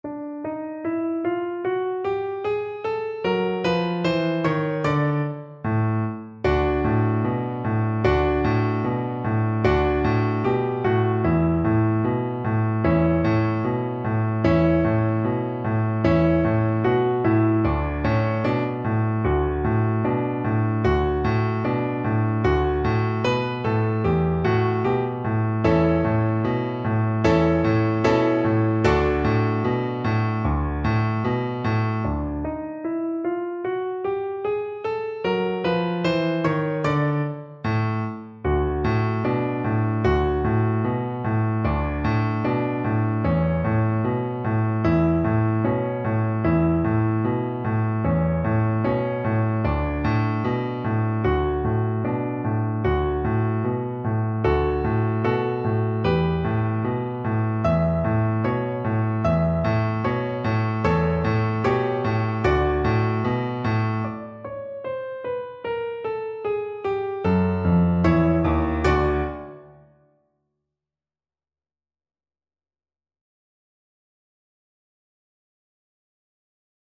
2/4 (View more 2/4 Music)
Energico
Pop (View more Pop Cello Music)